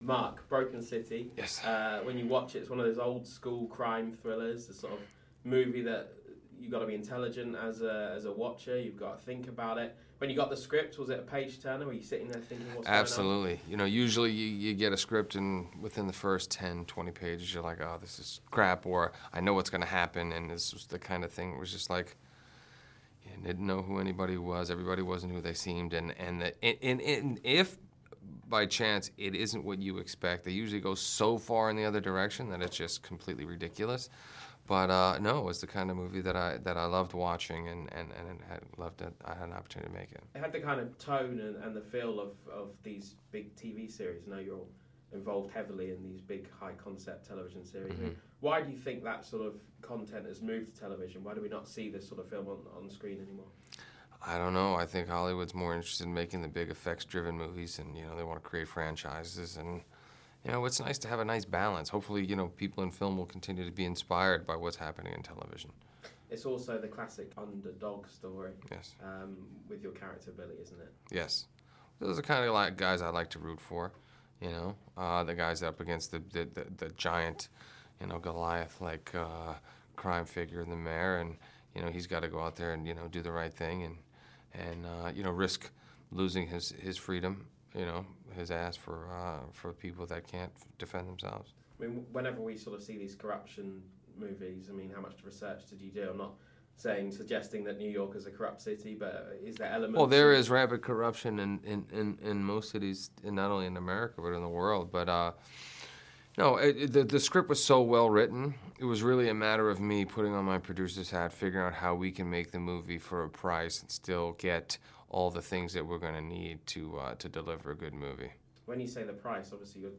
Mark Wahlberg talks to Sky News Radio